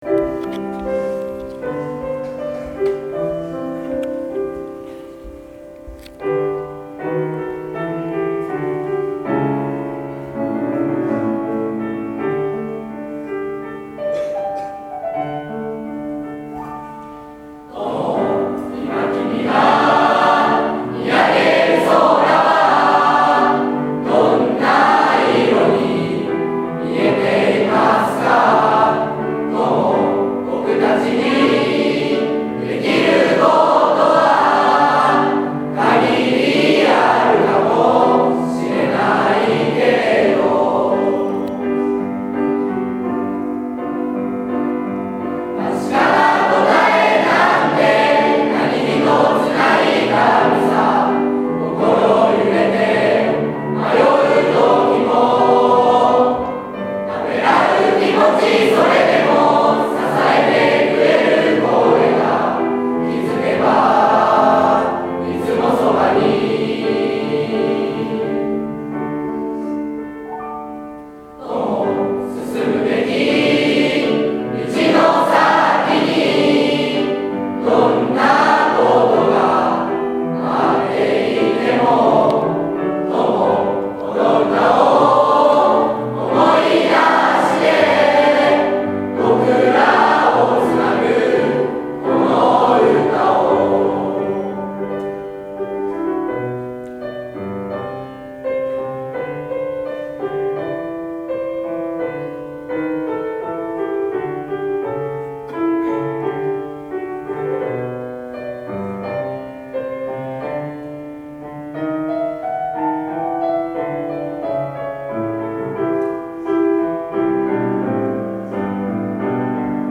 【写真上】在校生が卒業生にむけて作ったコサージュの贈呈 【写真中】卒業生代表による在校生に向けてのあいさつ 【写真下】卒業生が共同制作した校歌 卒業生を送る会での歌 在校生「大切なもの」 卒業生「友〜旅立ちの時〜」 ↑クリックする音楽が流れます（音量に気を付けてください）